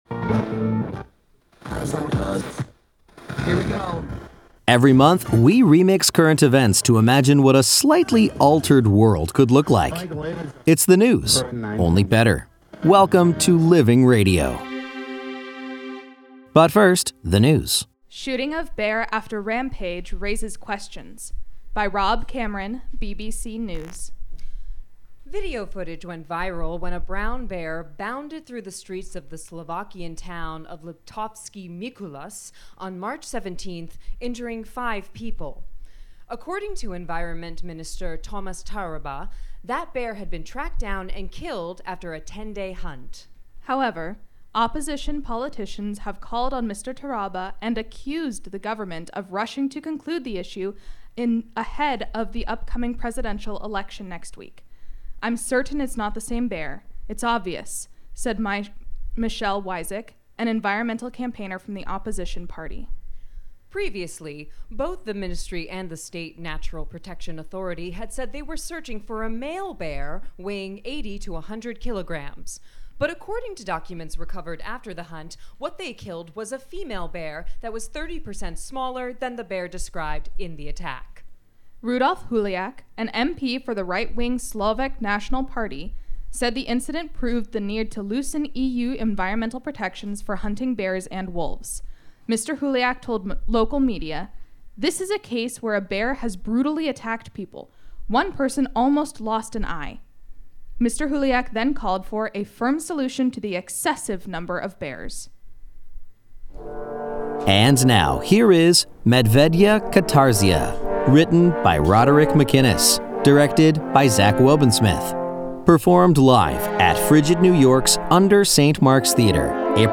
performed live for Living Radio at FRIGID New York’s UNDER St. Mark’s Theater, April 1, 2024